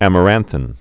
(ămə-rănthĭn, -thīn)